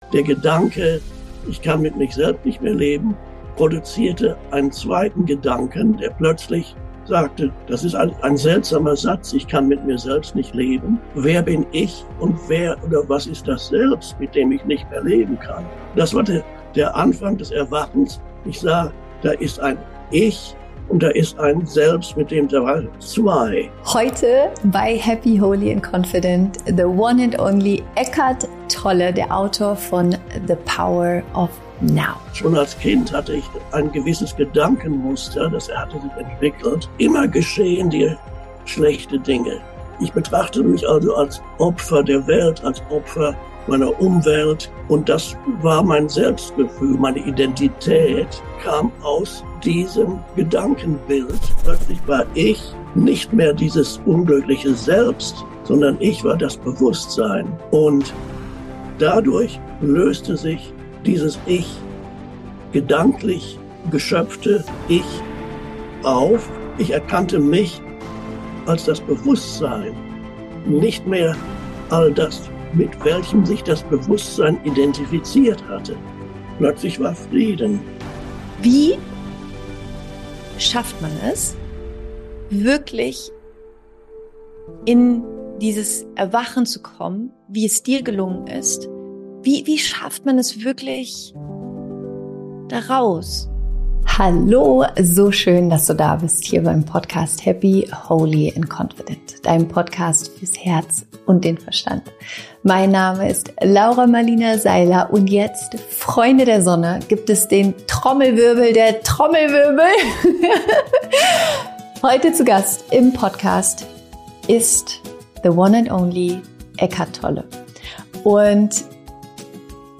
Es ist ein Interview, dass ich schon so lange führen wollte und ich bin so dankbar, es endlich mit dir teilen zu können.